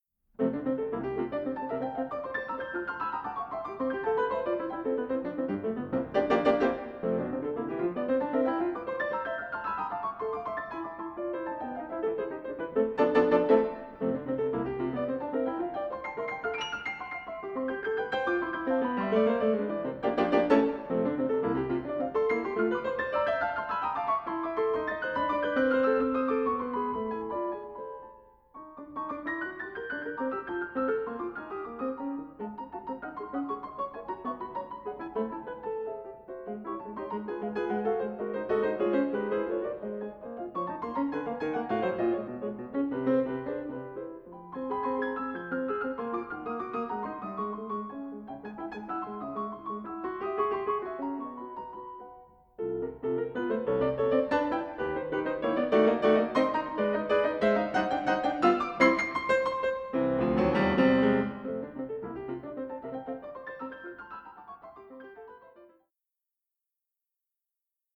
these solo piano works, both charming and imposing